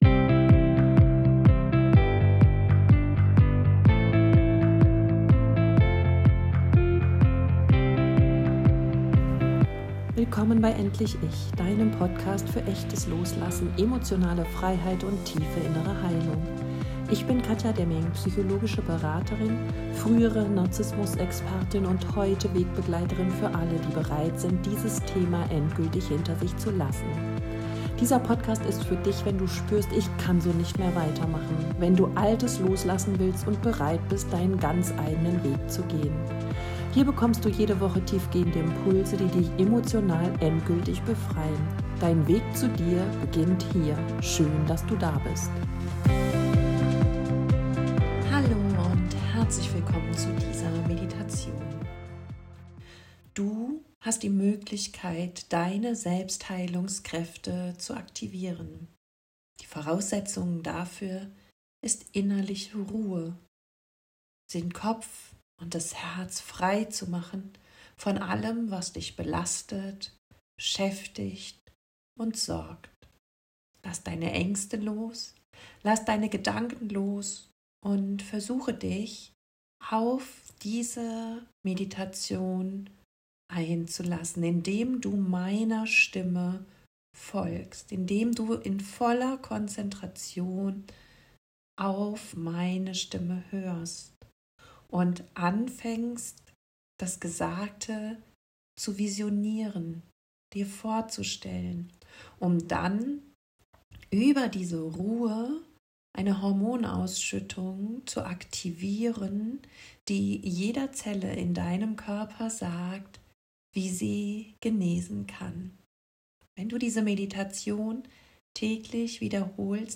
#328 Geführte Meditation zur Aktivierung deiner inneren Heilkräfte ~ Endlich ICH! Podcast